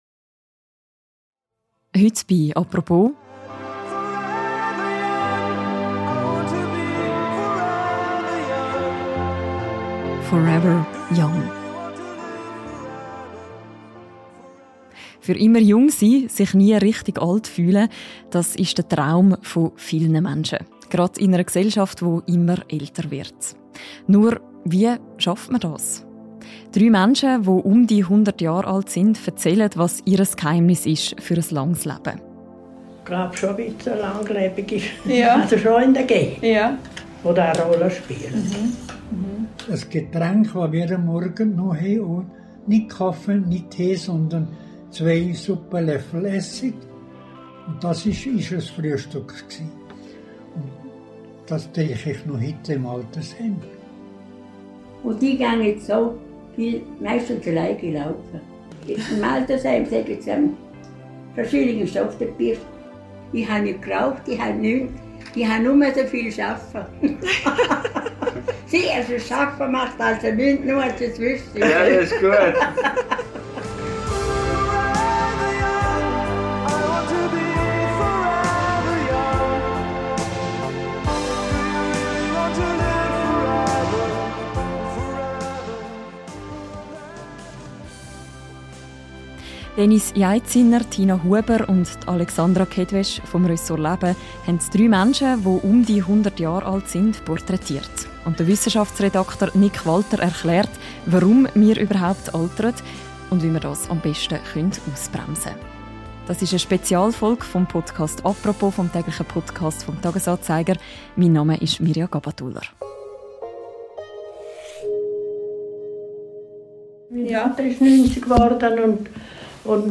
Was braucht es, um das Altern auszubremsen – und sich auch mit vielen Lebenjahren jung zu fühlen? In einer Spezialfolge des täglichen Podcasts «Apropos» erzählen drei Menschen, alle um die 100 Jahre alt, was ihr Geheimnis ist.